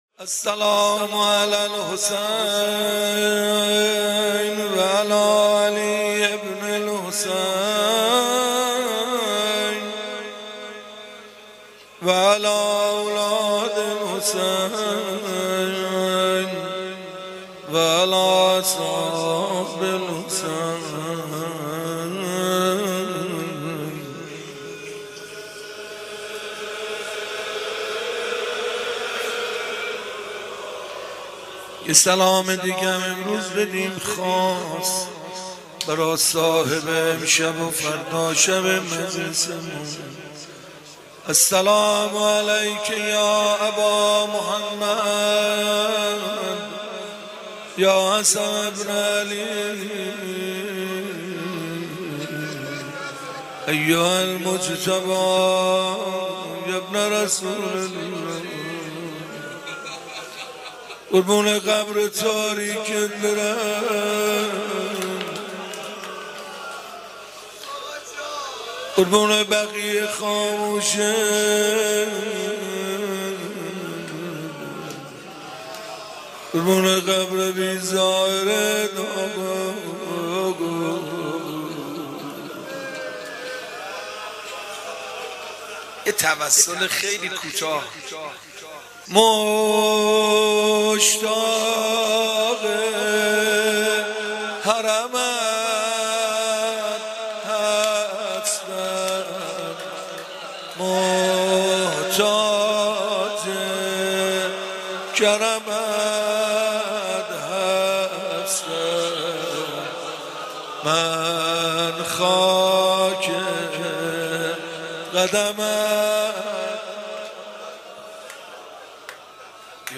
روضه شب پنجم